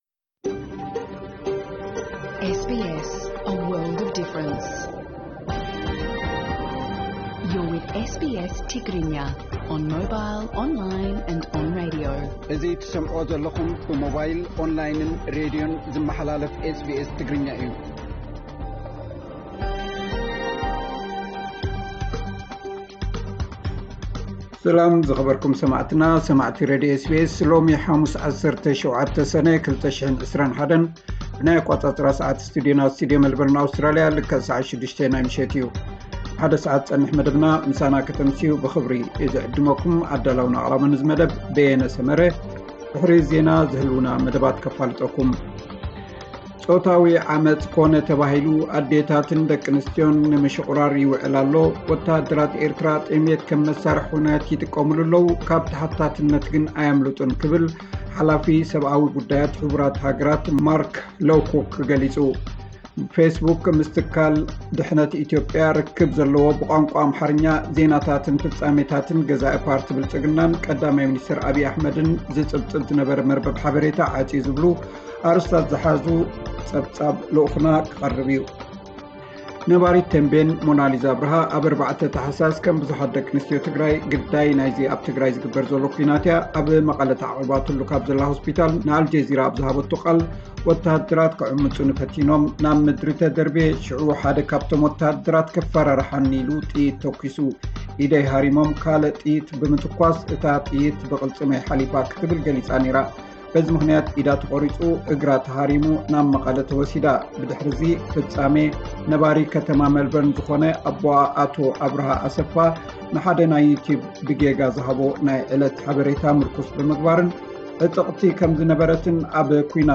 ዕለታዊ ዜና 17 ሰነ 2021 SBS ትግርኛ